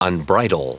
Prononciation du mot unbridle en anglais (fichier audio)
Prononciation du mot : unbridle